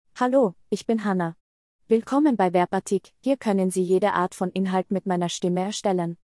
HannahFemale Austrian German AI voice
Hannah is a female AI voice for Austrian German.
Voice sample
Listen to Hannah's female Austrian German voice.
Hannah delivers clear pronunciation with authentic Austrian German intonation, making your content sound professionally produced.